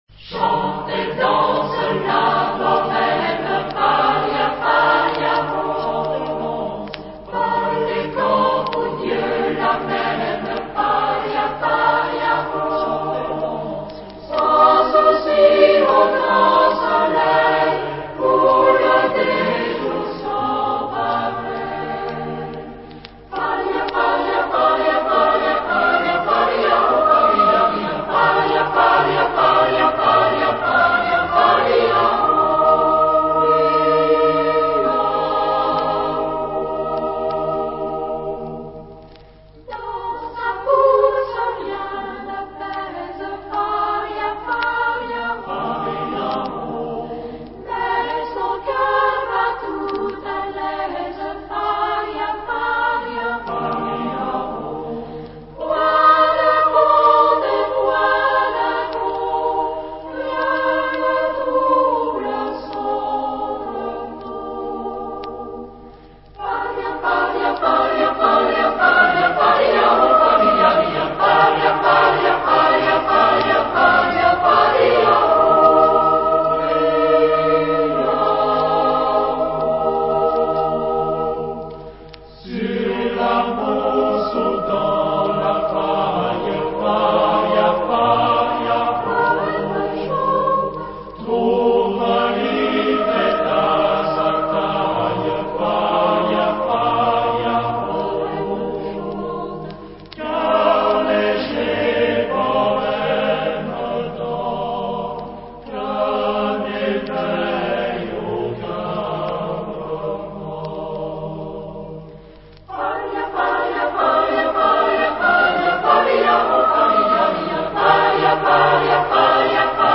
Harm. : Geoffray, César (1901-1972) [ France ]
Genre-Style-Forme : Populaire ; Chanson ; Danse ; Profane
Type de choeur : SATB  (4 voix mixtes )
Tonalité : ré majeur
interprété par La Psalette de Lyon dirigé par César Geoffray